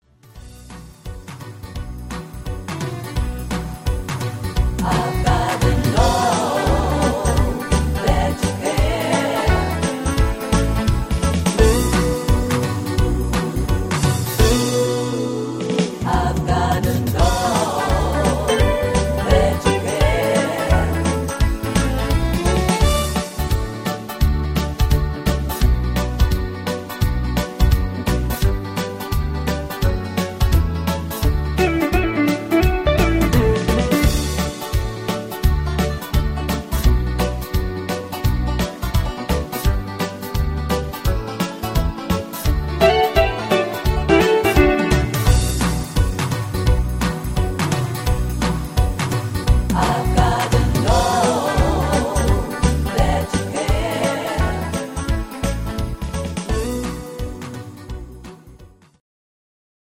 Buy Playback abmischen Buy